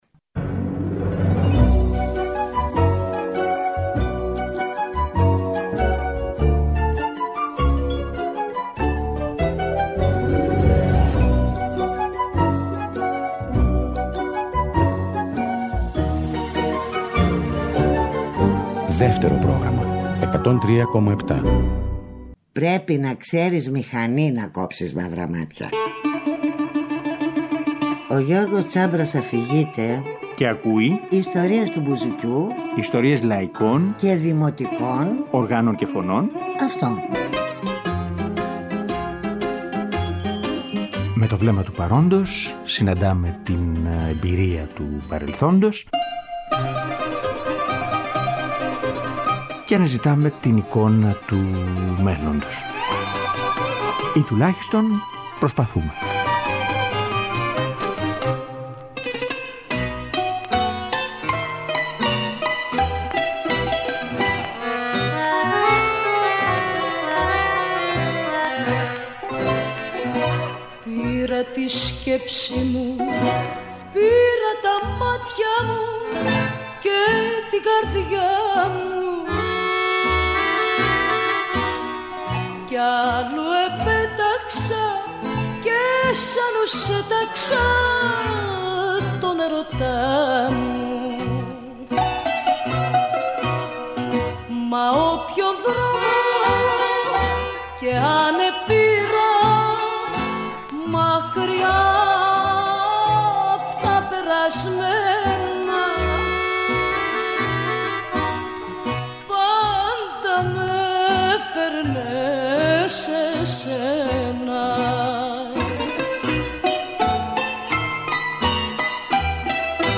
Σήμερα είπαμε να σταθούμε σε τραγούδια του που είπαν οι τέσσερεις συγκεκριμένες γυναικείες φωνές.
Αυτή η εκπομπή ωστόσο, στηριγμένη στις 4 συγκεκριμένες γυναικείες φωνές, θα τελειώσει με μια αντρική.